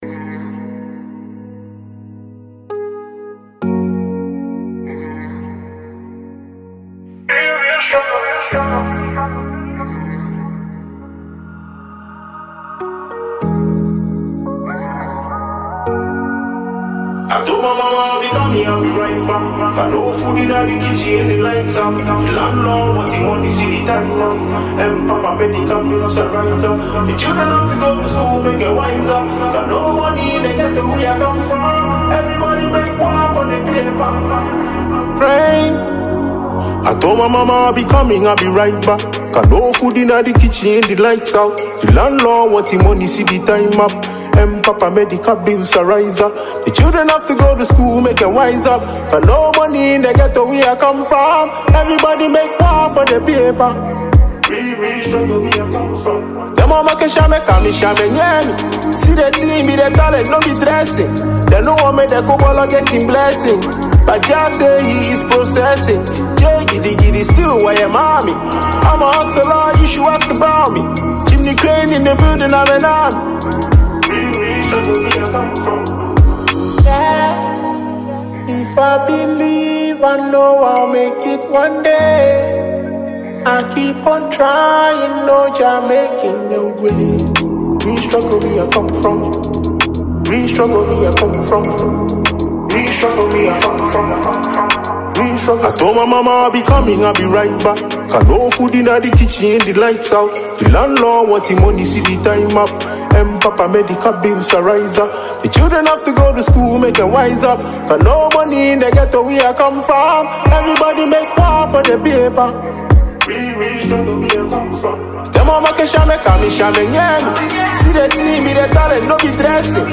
a powerful track driven by raw emotion and lyrical depth.
With its soulful production and uplifting message